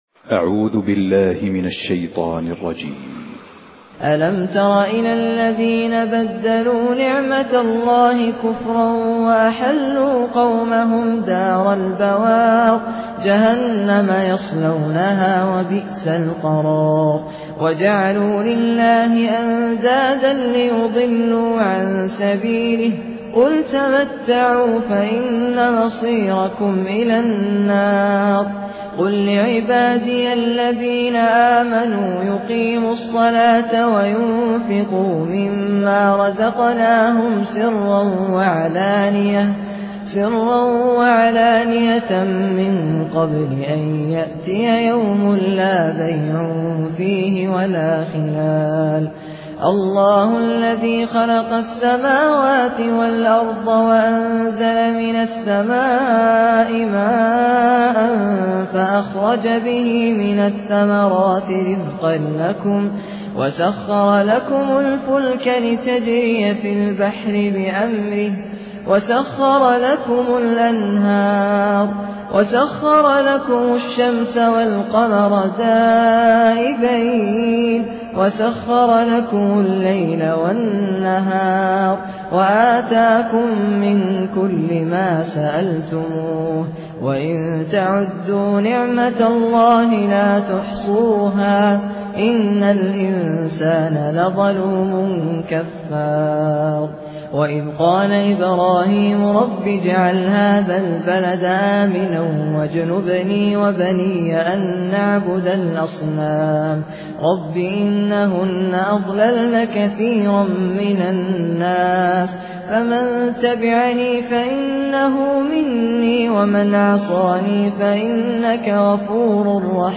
Quran recitations
Humble, distinctive recitations